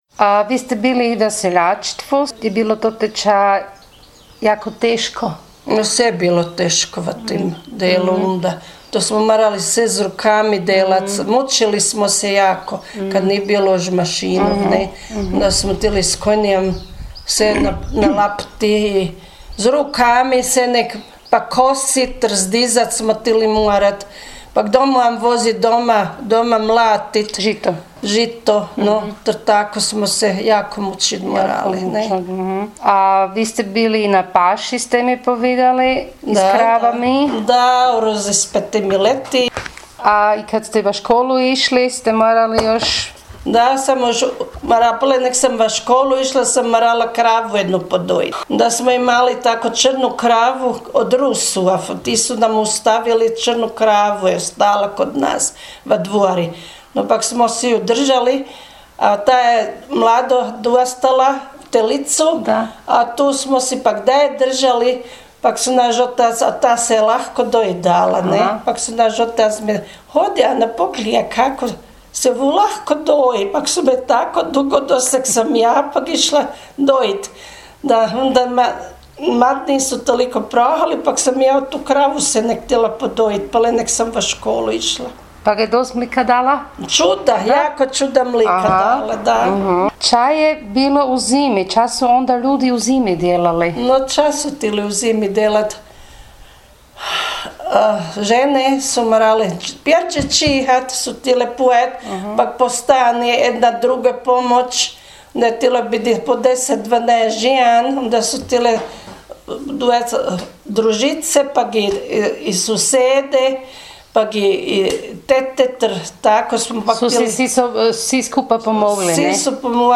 Kalištrof – Govor
22_Kalistrof_govor.mp3